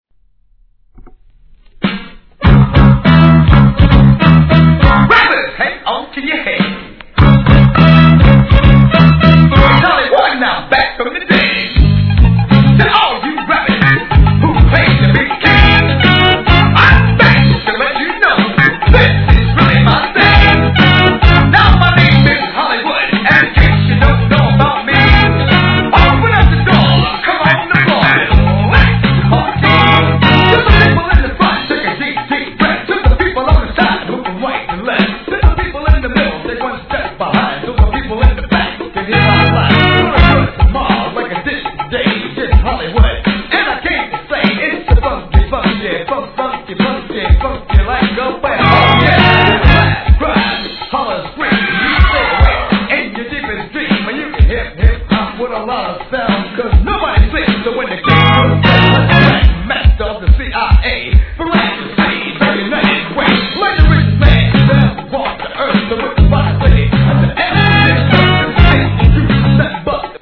HIP HOP/R&B
この古臭い煽りとコール＆レスポンスがたまらない！！